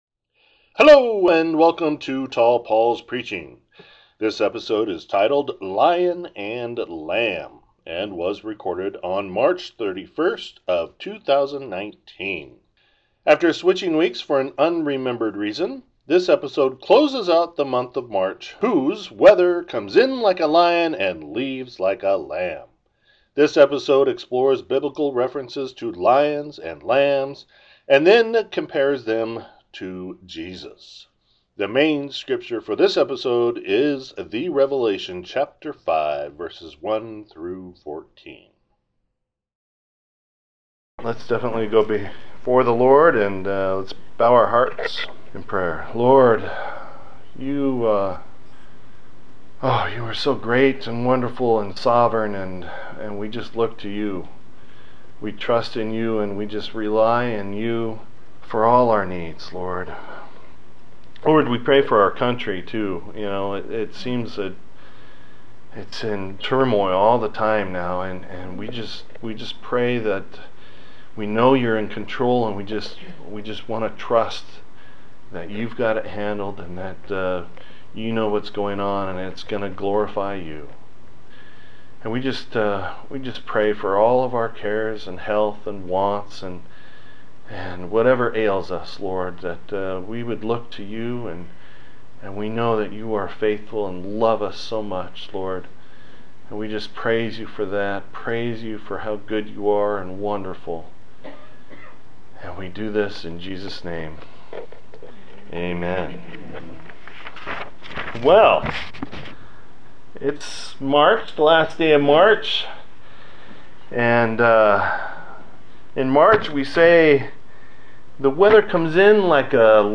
This sermon relates the weather of March, coming in like a lion and out like a lamb, to Jesus as both a lion of the tribe of Judah and as the Lamb of God. This episode takes a look at lions and lambs in the Bible and then closes with the main scripture of the Revelation chapter 5.